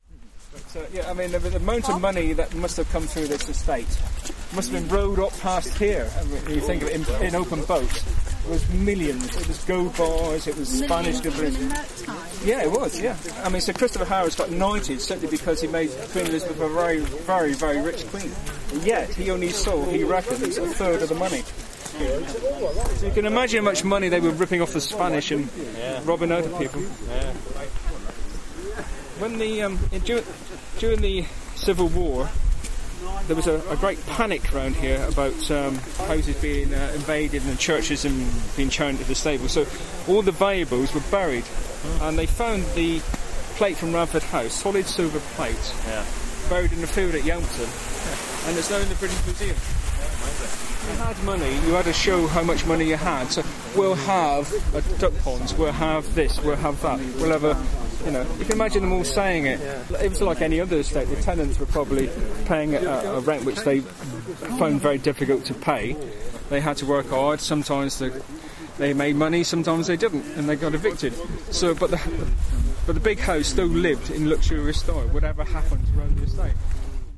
Audio Commentary from the Walk…